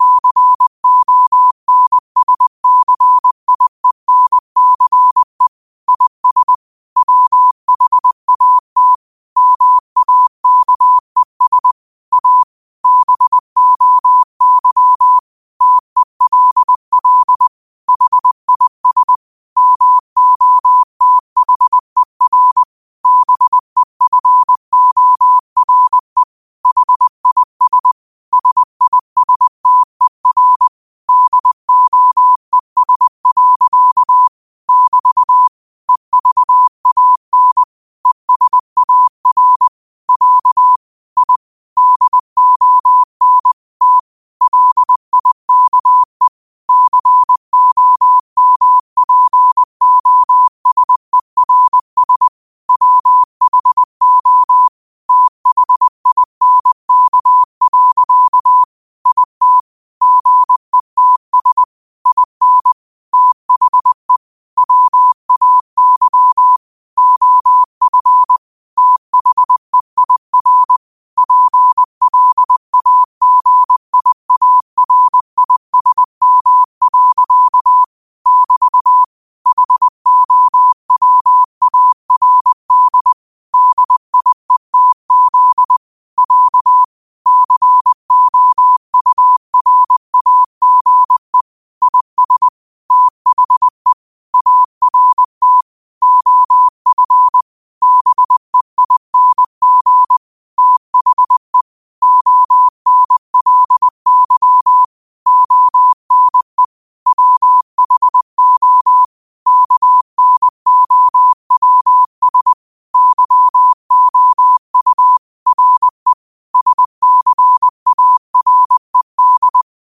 20 WPM morse code quotes for Tue, 12 Aug 2025 by QOTD at 20 WPM
Quotes for Tue, 12 Aug 2025 in Morse Code at 20 words per minute.